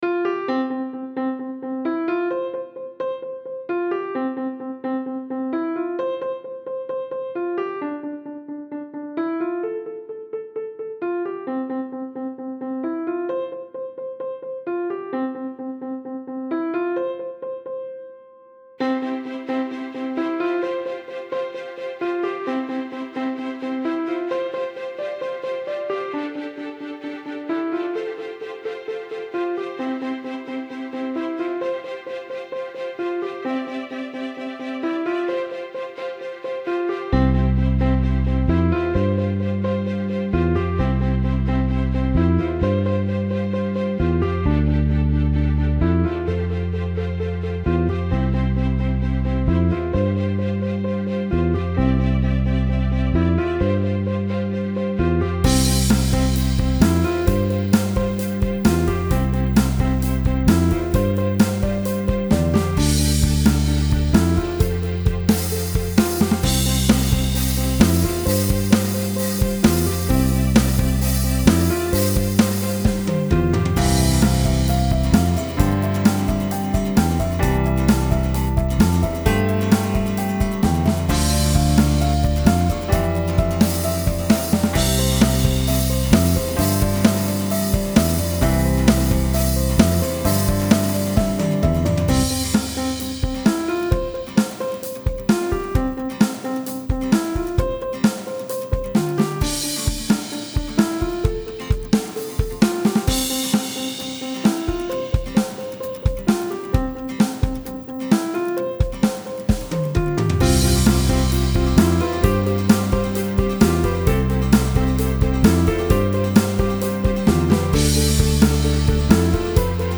Style Style Corporate, Pop, Soundtrack
Mood Mood Bright, Uplifting
Featured Featured Acoustic Guitar, Bass, Drums +1 more
BPM BPM 131